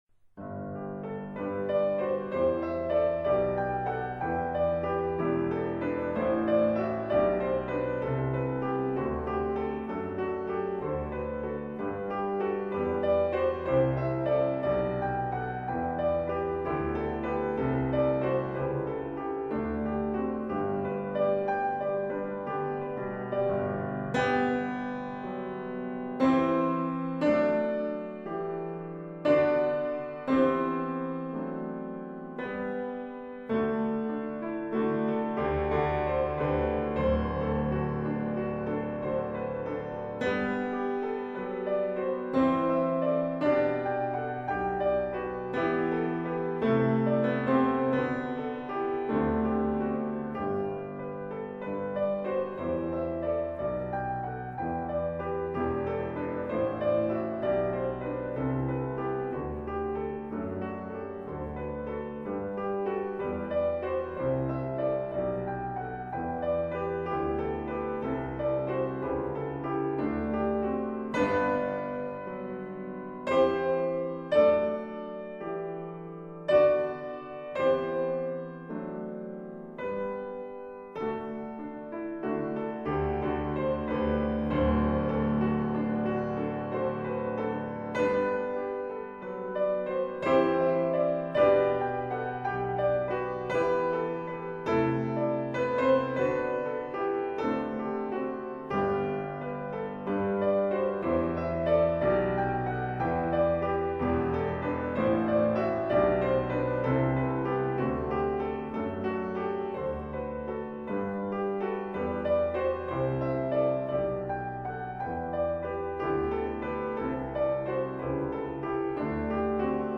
piano. Gravações realizadas em Müllem, Bélgica.